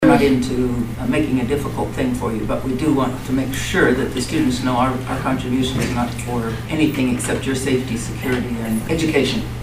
The first was a $400 contribution to the Onaga High School After Prom party, which Commissioner Dee McKee hopes will promote safety and security.